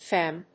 /fém(米国英語), fem(英国英語)/